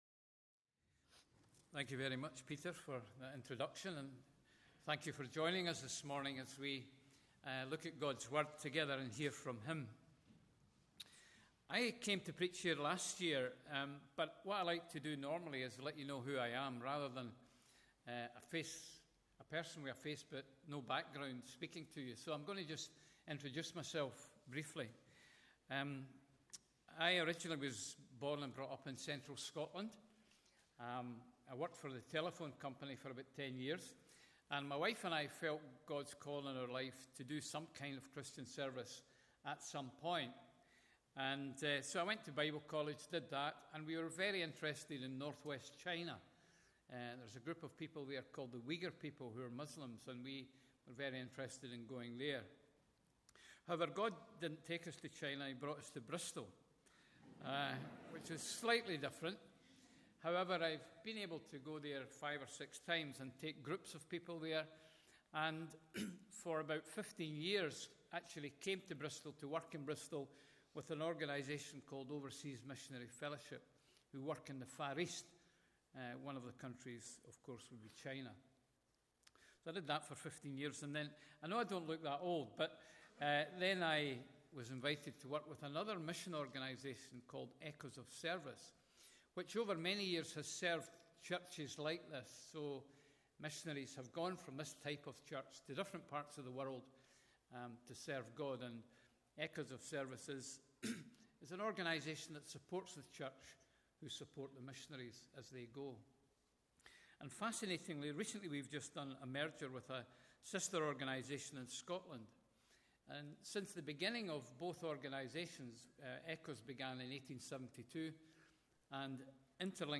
Bible Text: Judges 13-16 | Preacher: